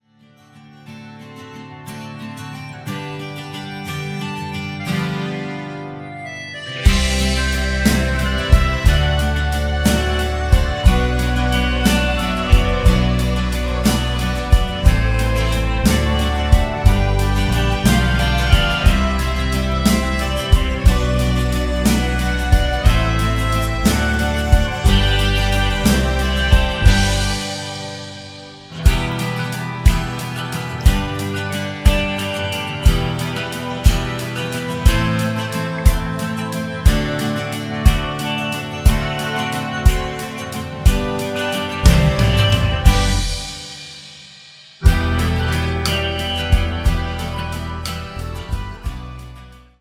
sans choeurs